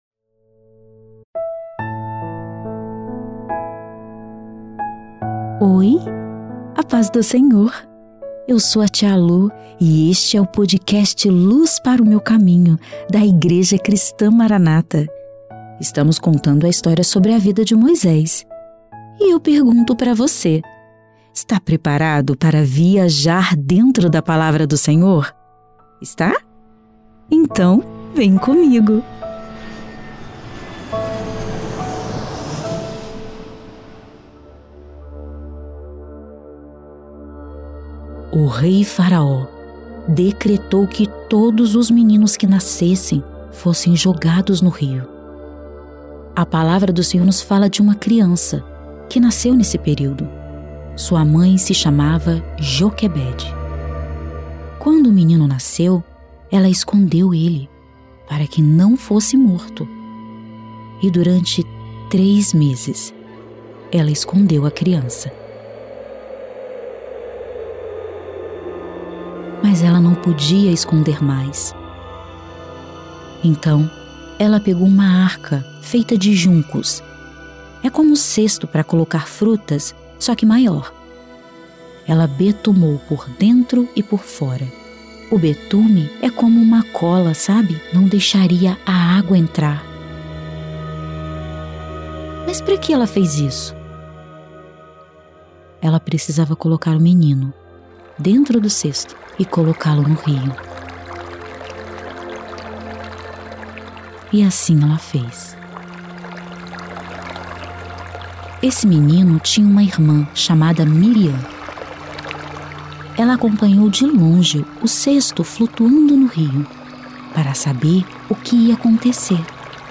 O quadro Luz Para o Meu Caminho traz histórias da bíblia narradas em uma linguagem para o público infantil.